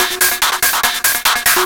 DS 144-BPM B2.wav